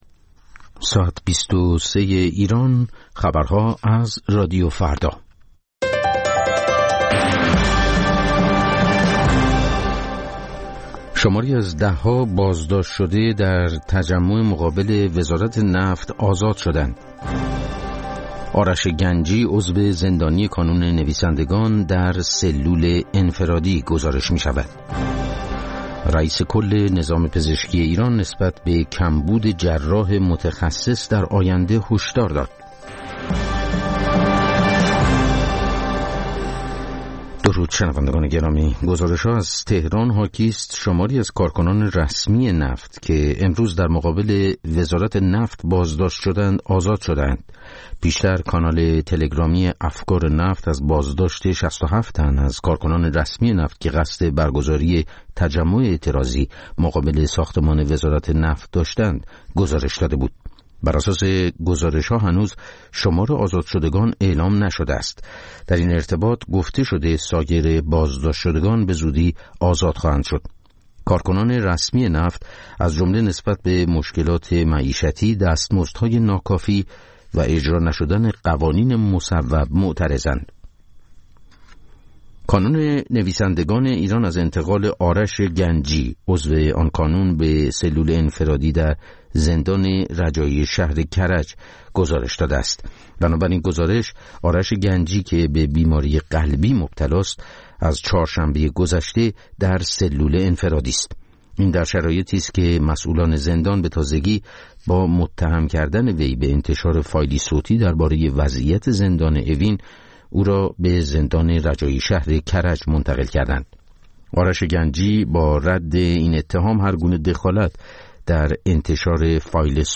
خبرها و گزارش‌ها ۲۳:۰۰